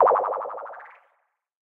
ricoshet_bonus.ogg